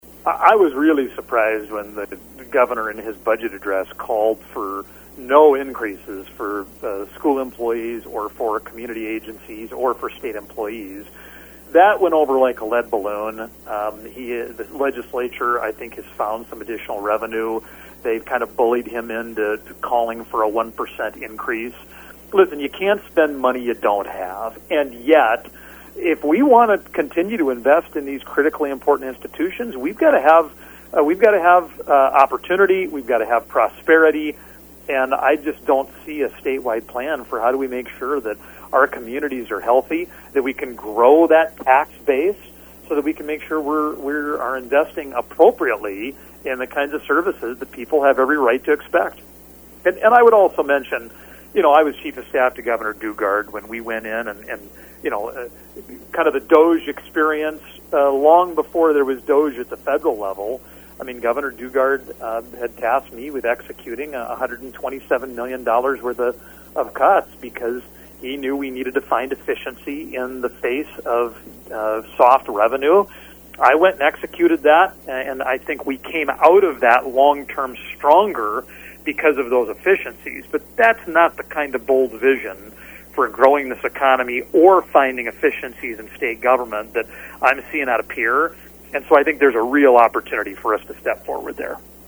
US Representative Dusty Johnson at a Educational Roundtable discussion Monday in Huron.